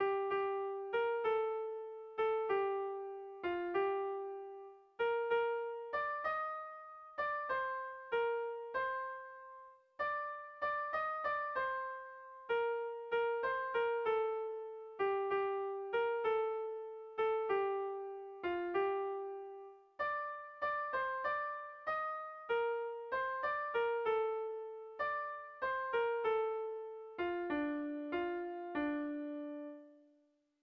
Sentimenduzkoa